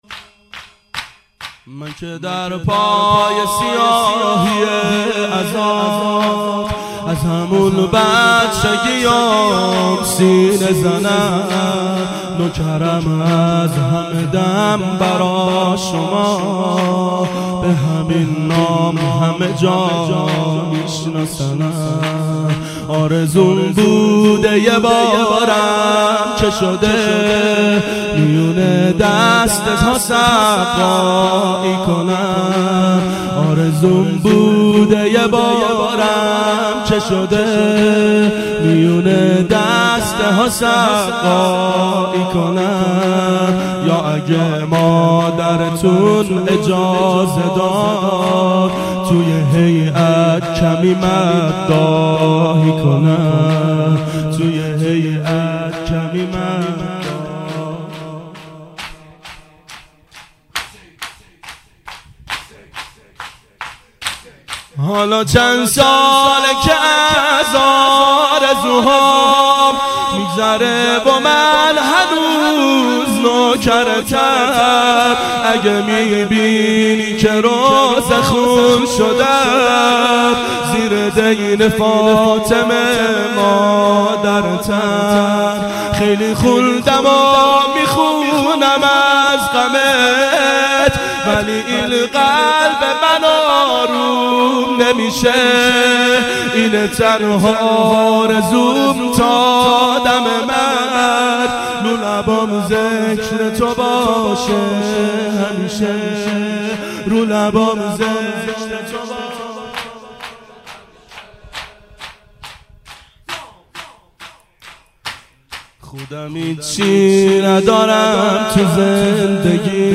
• ظهر اربعین سال 1389 محفل شیفتگان حضرت رقیه سلام الله علیها